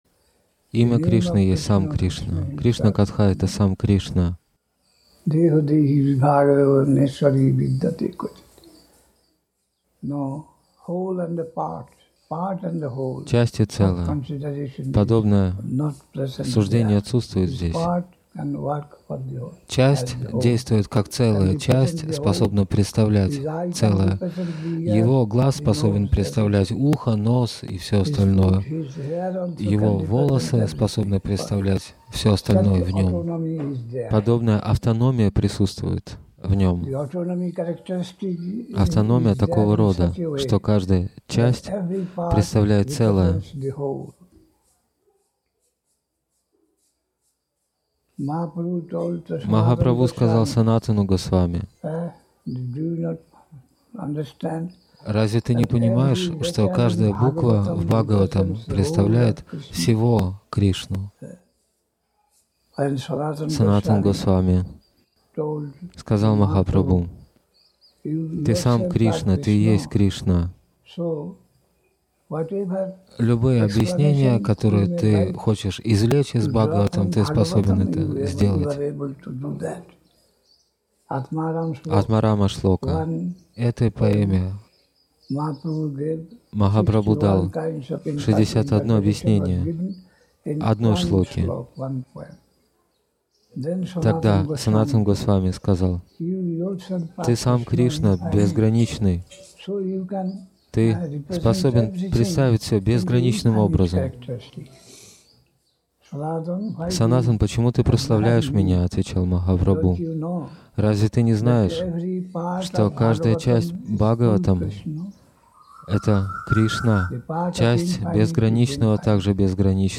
(28 ноября 1983 года. Навадвипа Дхама, Индия)